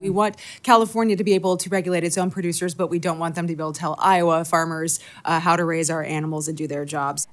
(Radio Iowa) – The U-S Agriculture Secretary says she’s open to federal action in response to a California state law that would restrict the way farmers in Iowa raise pigs. Iowa Congresswoman Ashley Hinson, a Republican from Marion, asked about the issue in a House budget hearing.